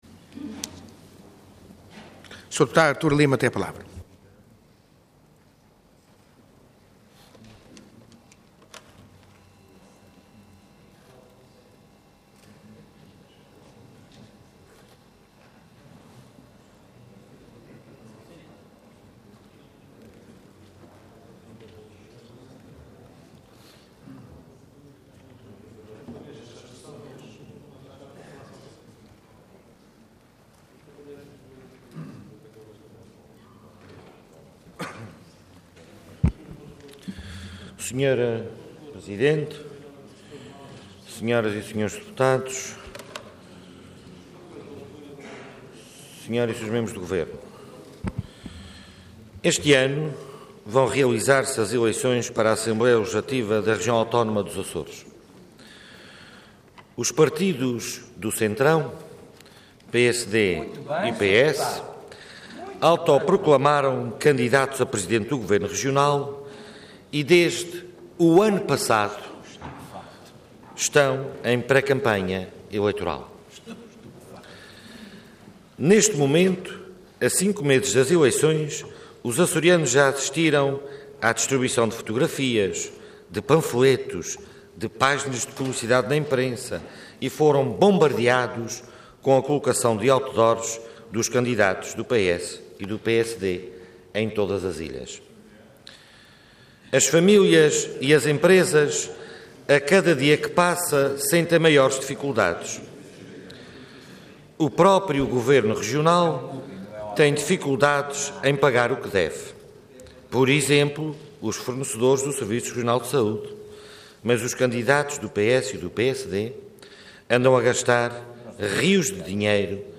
Parlamento online - Intervenção do Deputado Artur Lima.
Intervenção Declaração Política Orador Artur Lima Cargo Deputado Entidade CDS-PP